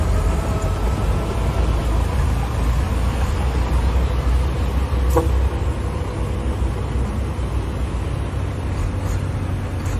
Kling-Audio-Eval / Sounds of other things /Engine /audio /19869.wav